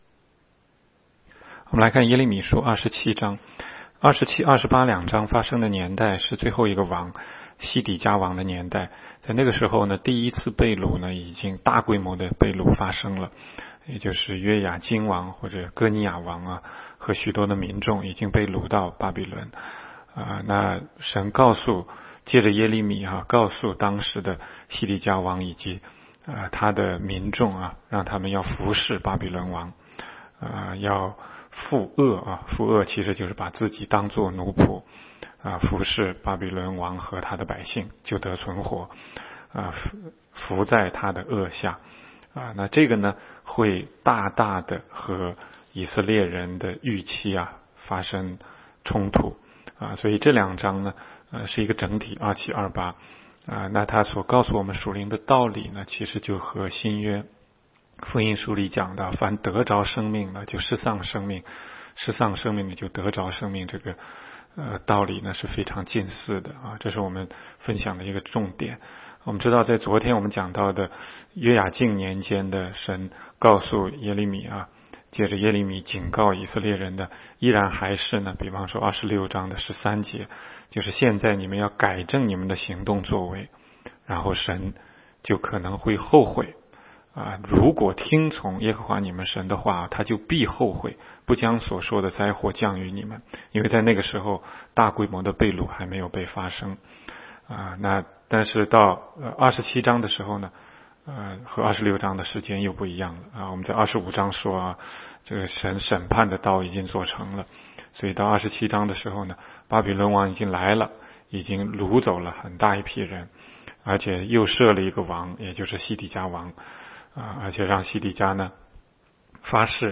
16街讲道录音 - 每日读经 -《耶利米书》27章